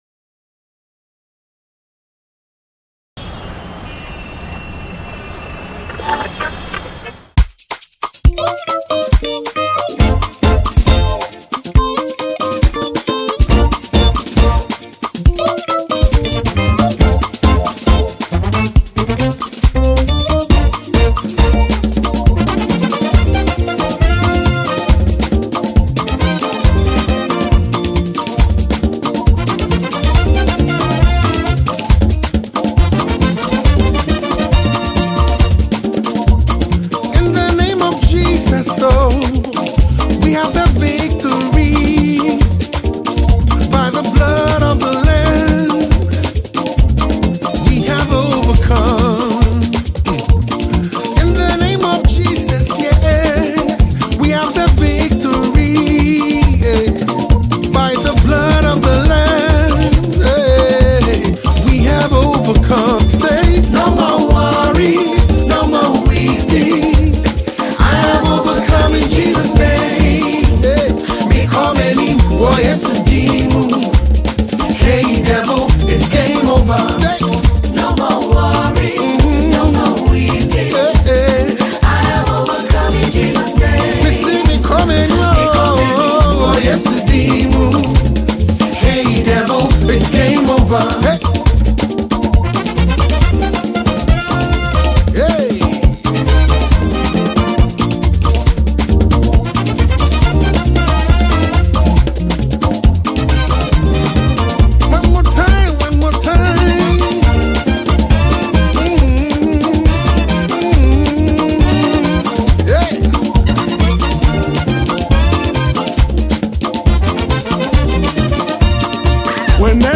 GospelMusic